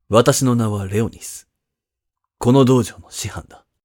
性別：男